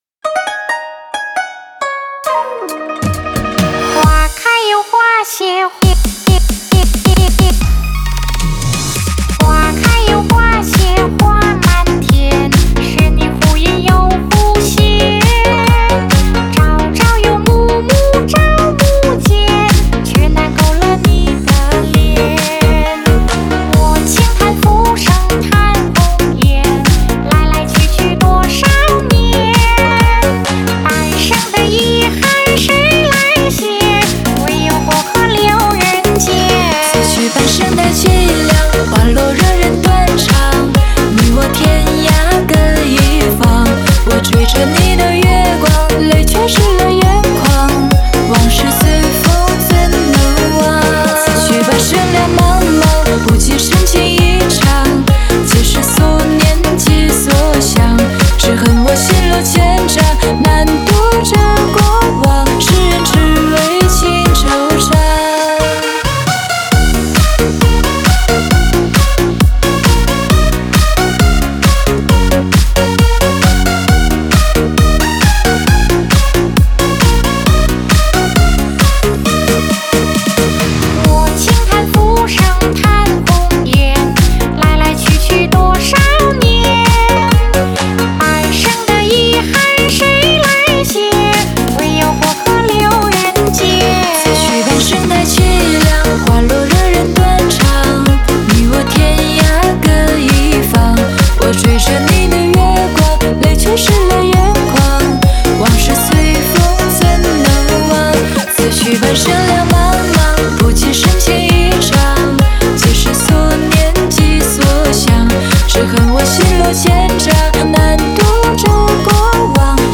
Ps：在线试听为压缩音质节选，体验无损音质请下载完整版
和声